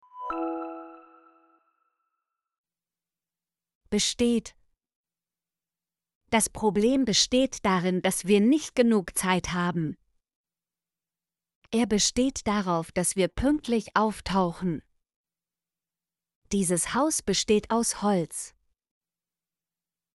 besteht - Example Sentences & Pronunciation, German Frequency List